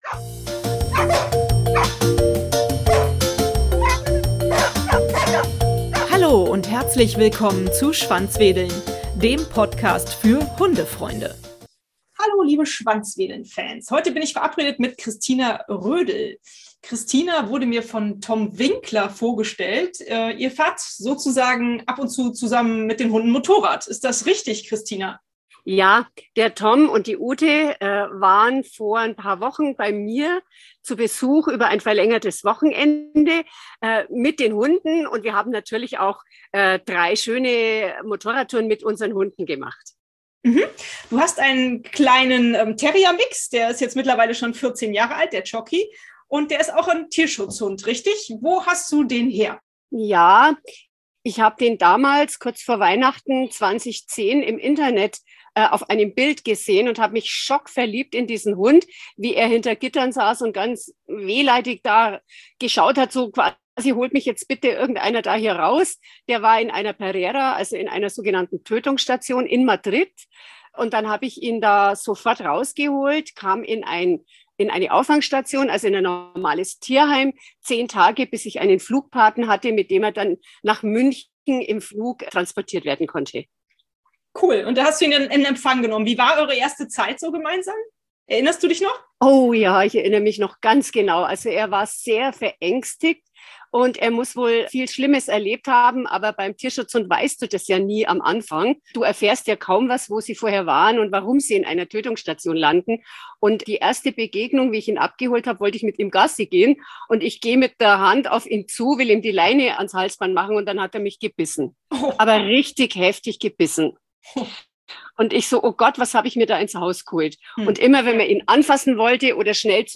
Lasst Euch überraschen von diesem spannenden Interview: